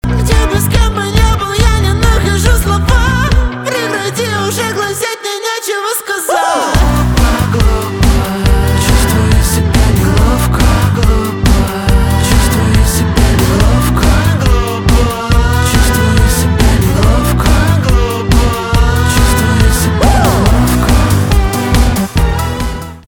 альтернатива
гитара , барабаны